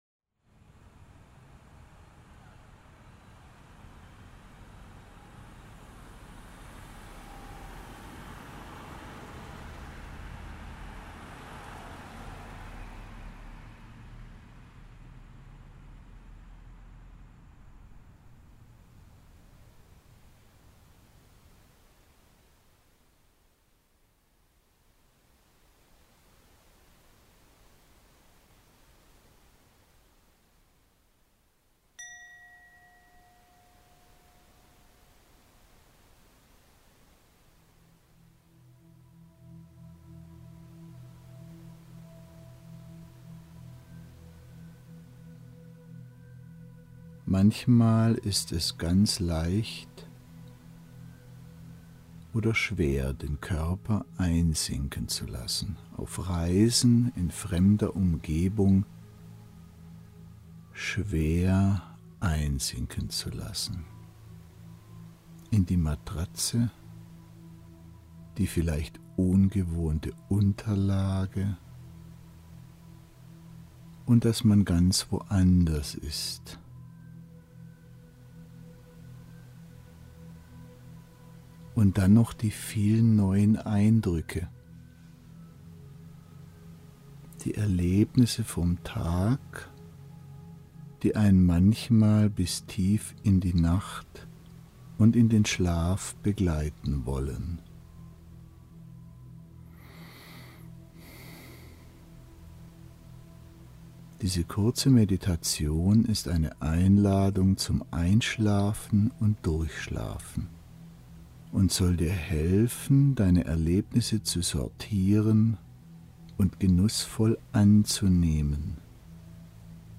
Schlafmeditation-Reise-k.mp3